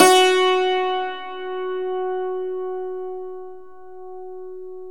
Index of /90_sSampleCDs/E-MU Formula 4000 Series Vol. 4 – Earth Tones/Default Folder/Hammer Dulcimer
DLCMR F#2-R.wav